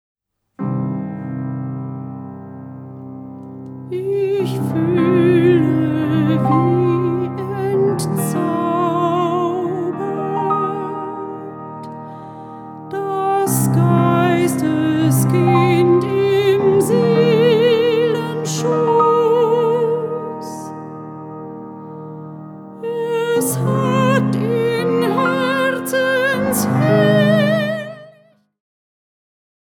Eine Vertonung als Liederzyklus
Gesang
Klavier